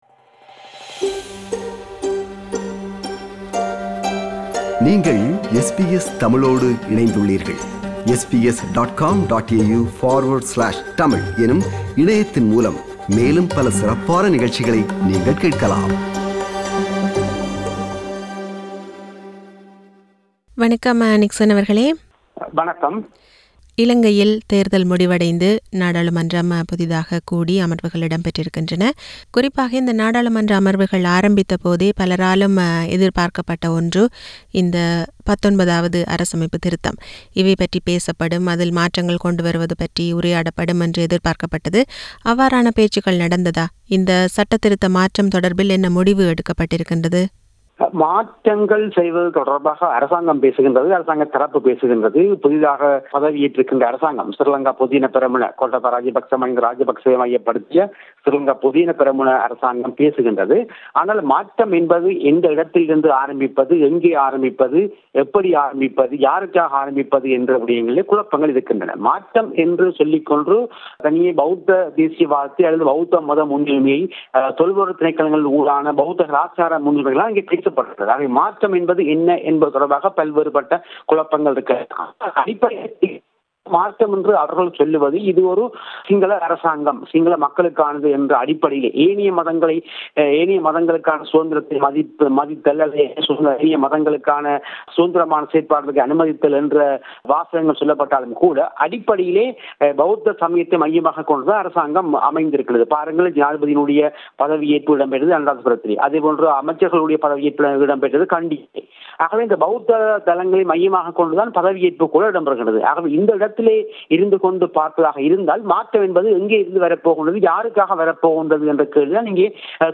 Analysis: Inaugural session of the 9th Parliament of Sri Lanka